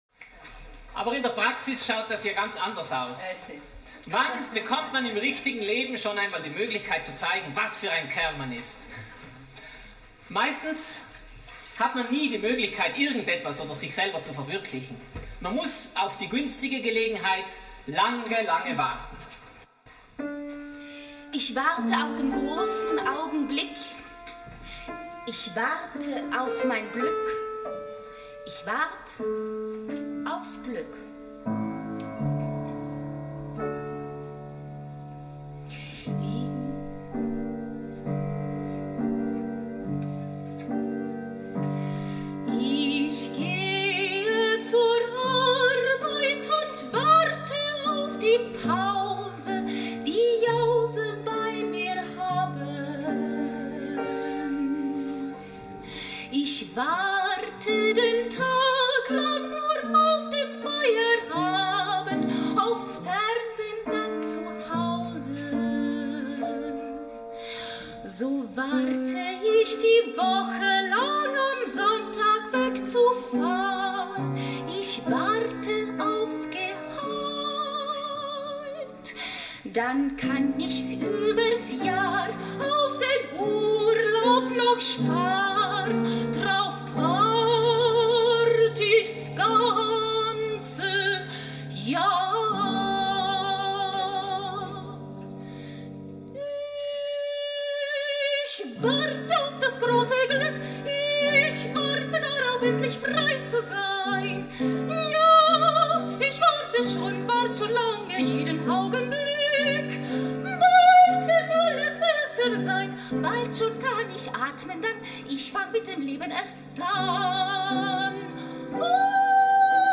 Es handelt sich um eine Live-Aufnahme während einer privaten Soiree, die wir für das WEB stark komprimiert haben, wir bitten um Nachsicht bei der Tonqualität – es geht uns hier nicht um HiFi, sondern um Inhalt!
Klavier
Gesang
Foto: TELOSSprache, Gesang und Musik, 3:46, mp3, 1,5 MB  >>